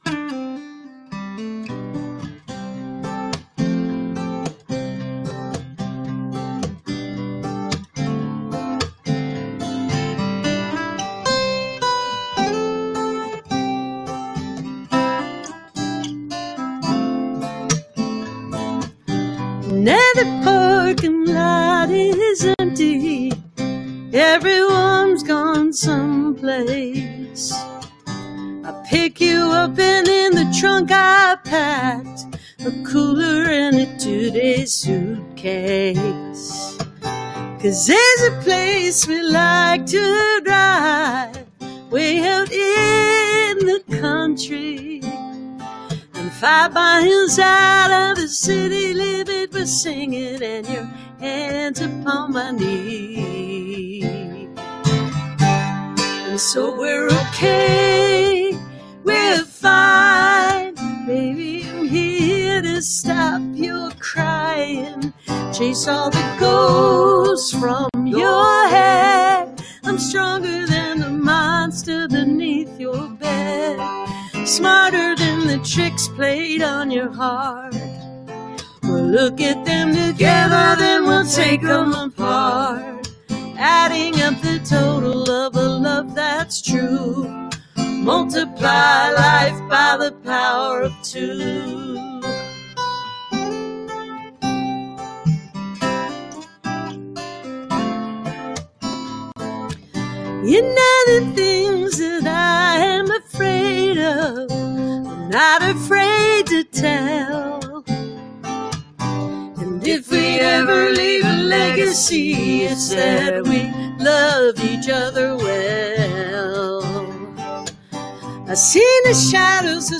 (captured from the youtube video stream)